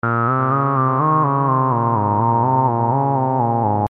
描述：嘻哈饶舌说唱|忧郁
Tag: 贝司 合成器